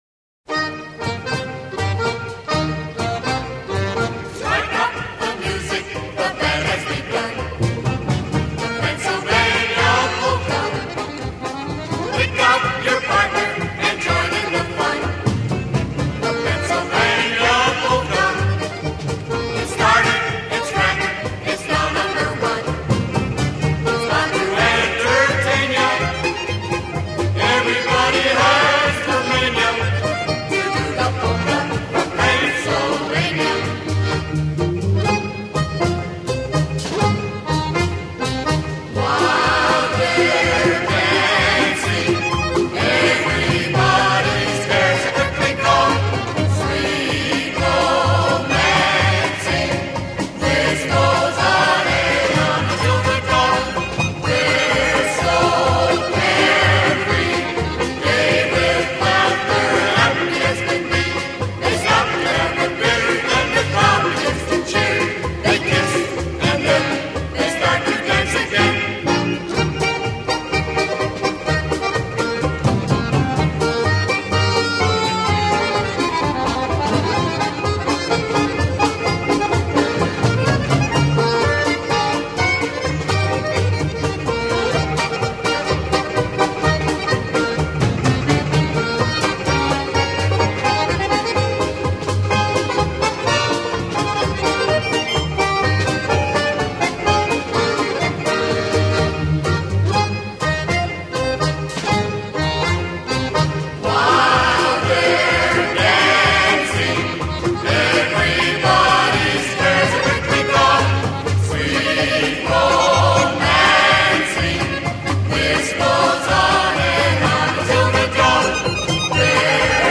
Полька